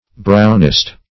Brownist \Brown"ist\, n. (Eccl. Hist.)